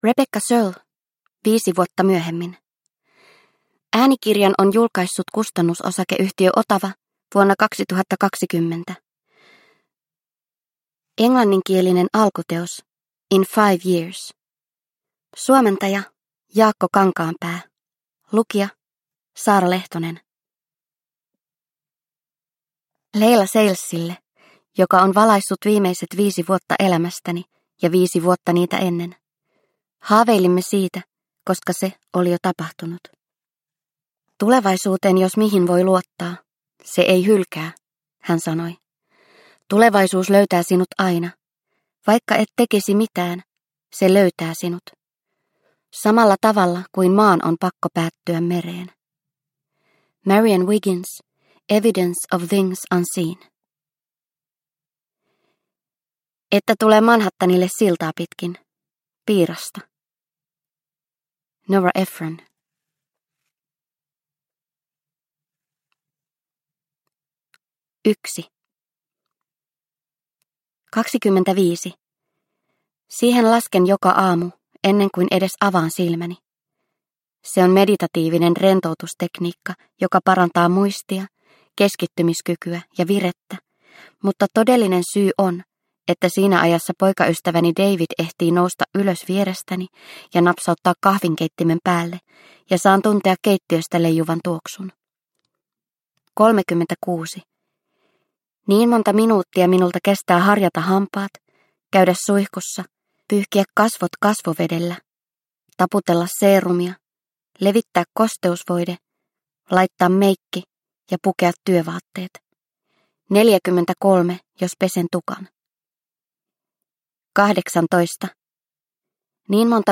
Viisi vuotta myöhemmin – Ljudbok – Laddas ner